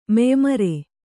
♪ meymare